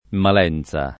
Malenza
[ mal $ n Z a ]